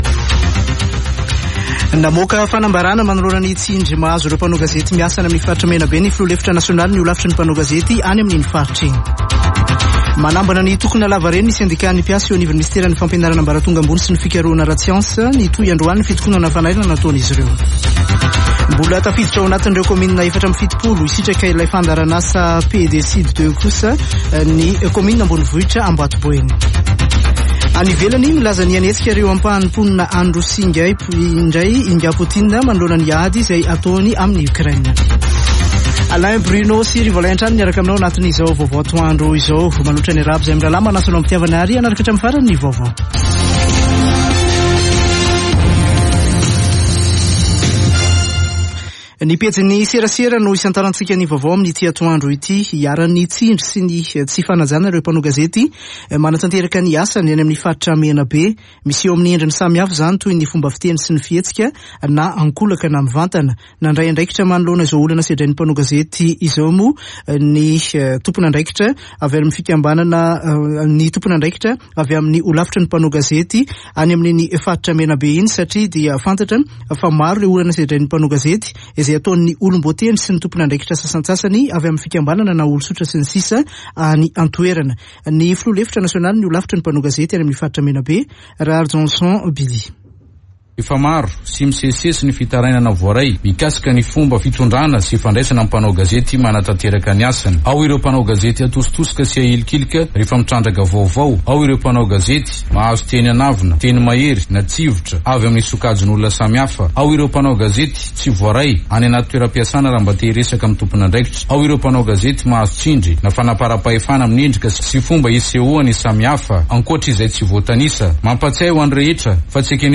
[Vaovao antoandro] Alakamisy 22 septambra 2022